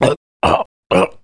SCI-LASTBREATH5.mp3